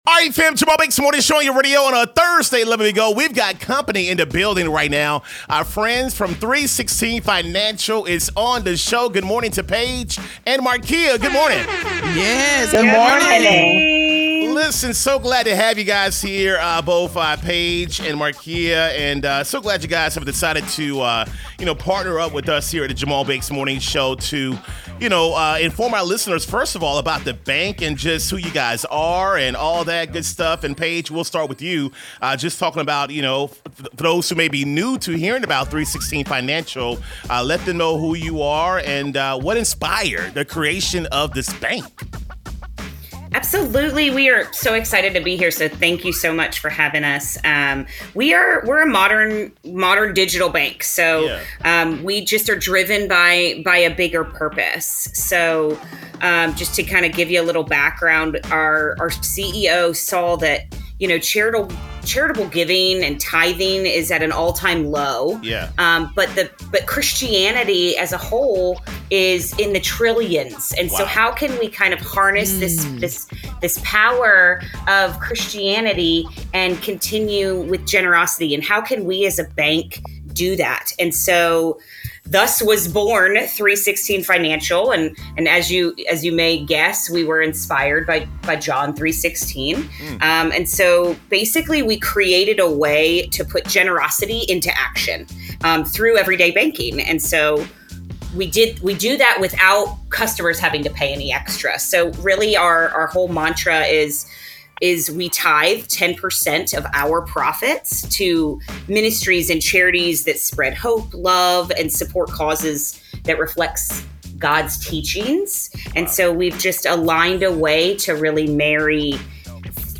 full interview to hear the “why” behind 316 Financial and discover how your banking can become an expression of faith.